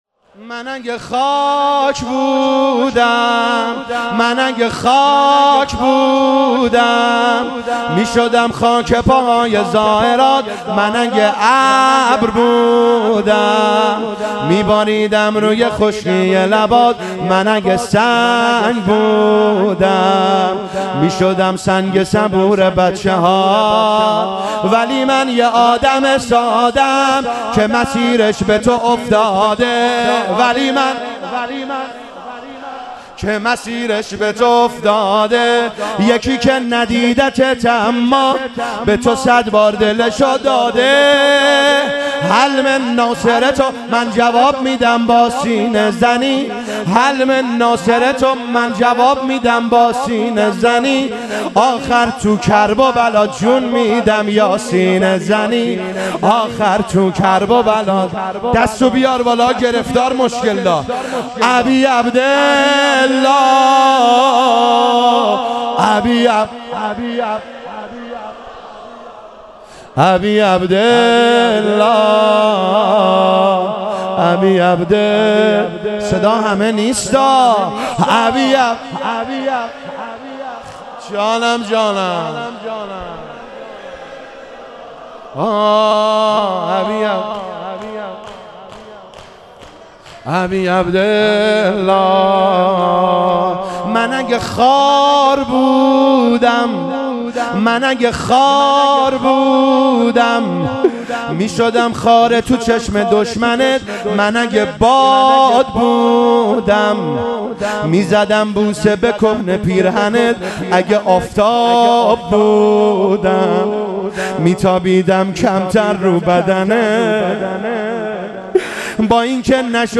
شب بیست وهفتم رمضان 1401